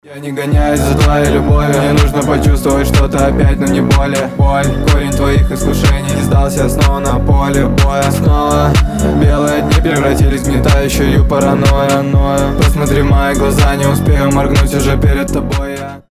• Качество: 320, Stereo
атмосферные
Electronic
glitch hop